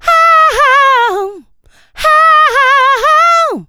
HA HA HAN.wav